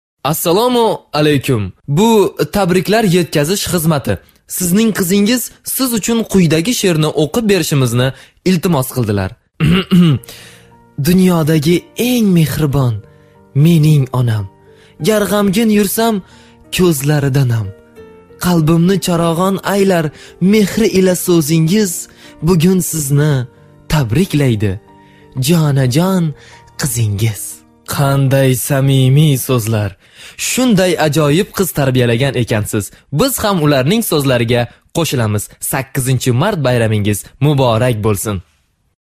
Özbekçe Seslendirme
Özbekçe seslendirme için profesyonel ve Anadili Özbekçe olan seslendirme sanatçılarımızla hizmetinizdeyiz
ERKEK SESLER